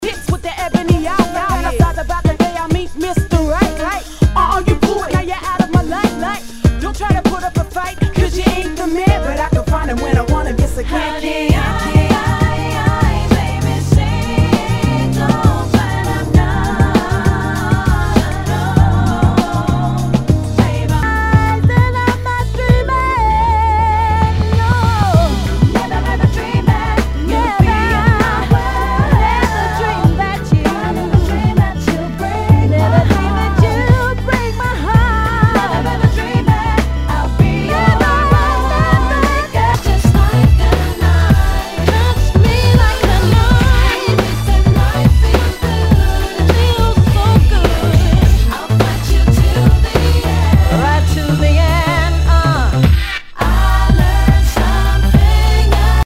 HIPHOP/R&B
ナイス！R&B/Hip-Hop！
全体にチリノイズが入ります